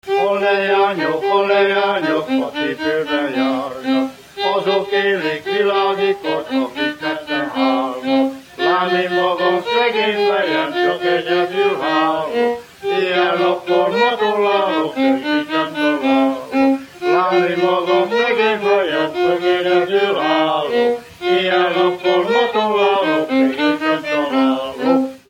Erdély - Hunyad vm. - Csernakeresztúr
Műfaj: Silladri
Stílus: 7. Régies kisambitusú dallamok